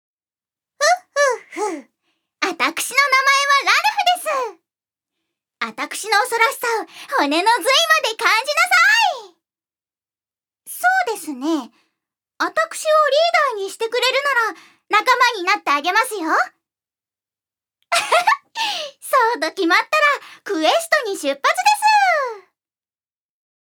預かり：女性
音声サンプル
セリフ２